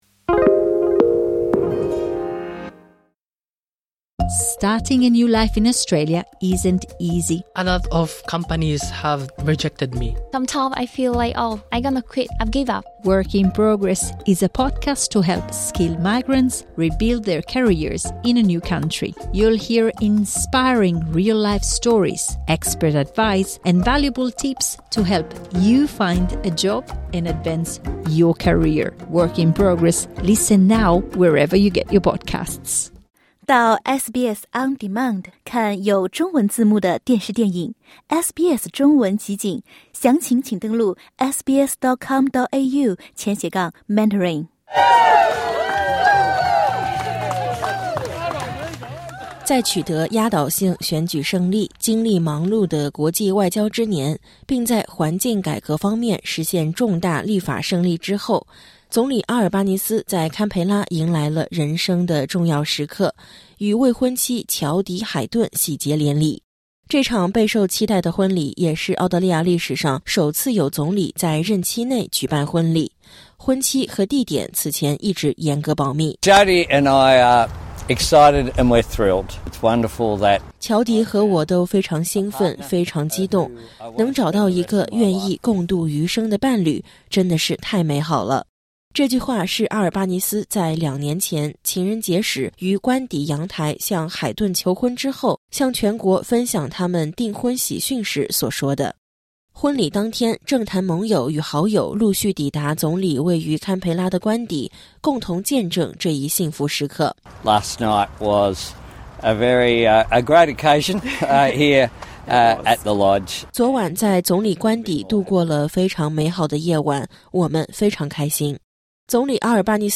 点击音频，收听完整报道。